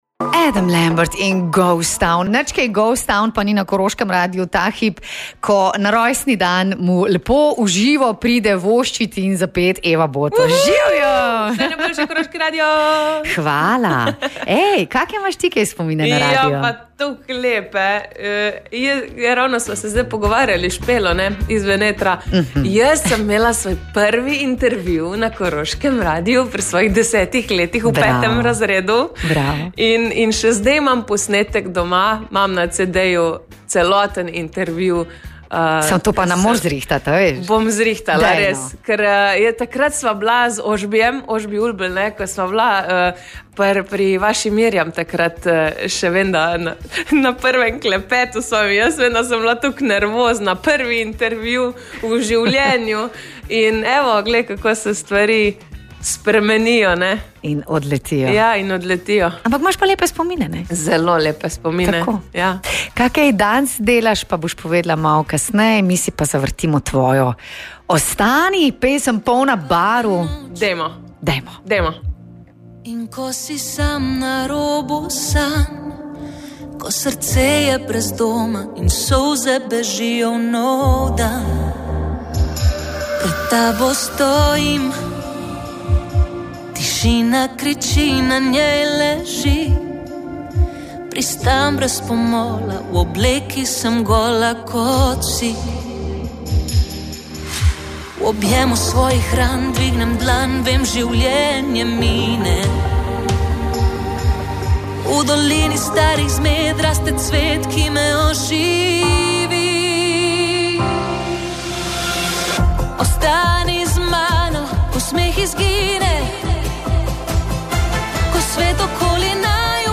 Evi Boto je v studiu delal družbo njen Lucky
Koroškemu radiu je danes v živo voščila tudi Eva Boto. Na naš radio ima še posebej lepe spomine, saj je tukaj opravila svoj čisto prvi intervju na začetku njene glasbene poti.